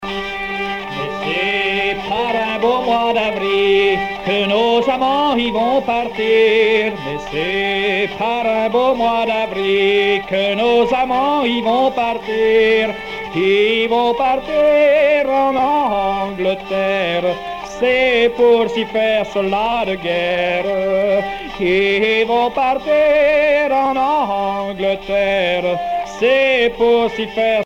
Genre strophique
Musique traditionnelle paysanne du Haut-Poitou